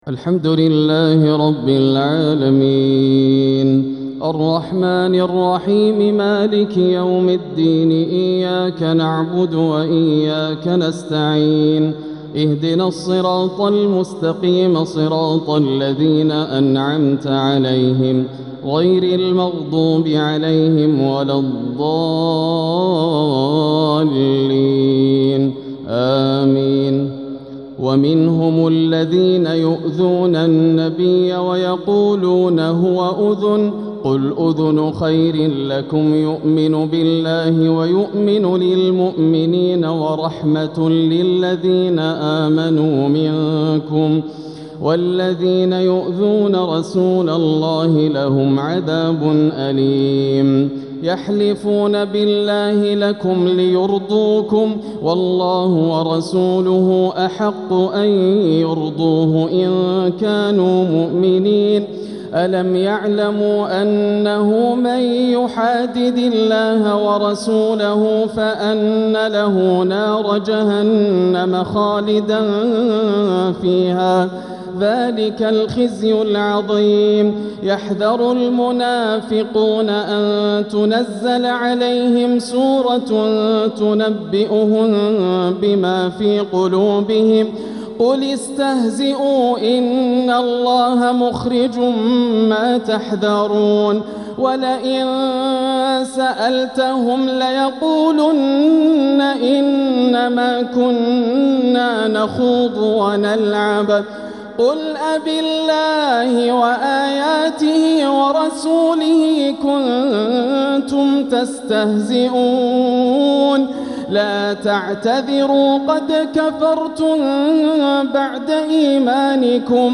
تراويح ليلة 14 رمضان 1446هـ من سورة التوبة (61-116) > الليالي الكاملة > رمضان 1446 هـ > التراويح - تلاوات ياسر الدوسري